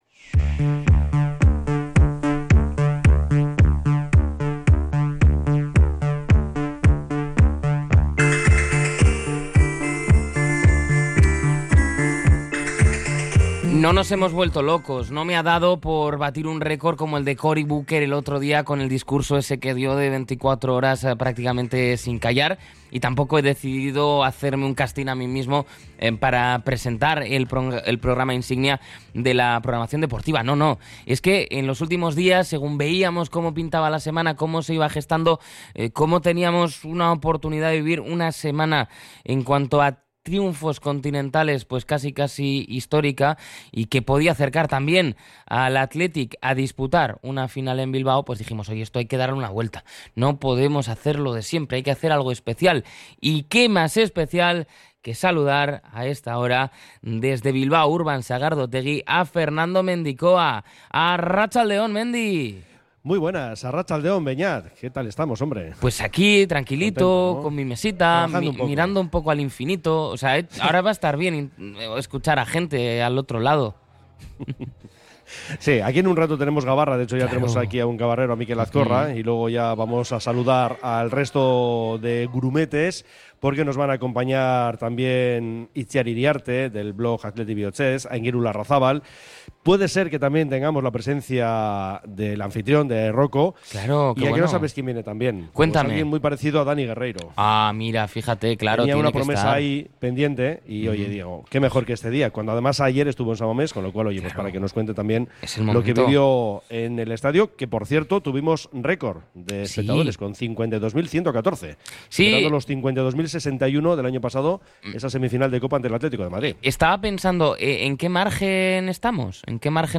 Tramo informativo de 13.30 a 14h